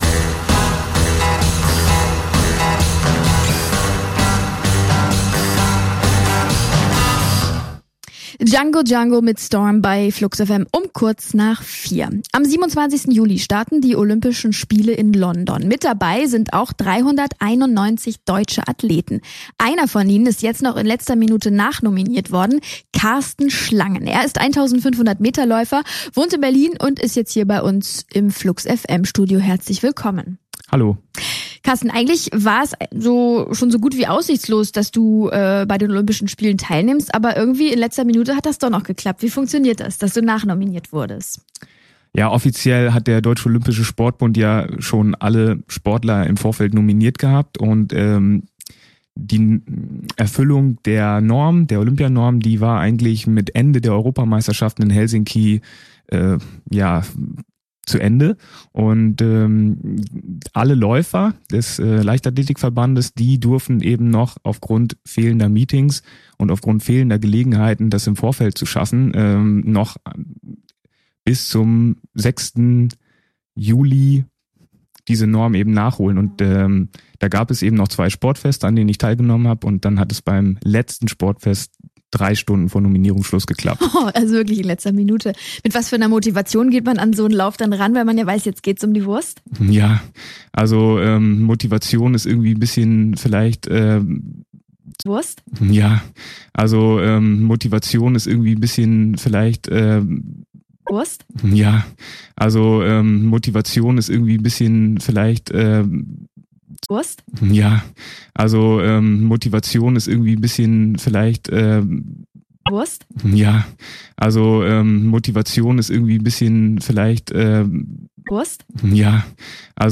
Flux-FM_Interview-Olympiaquali.mp3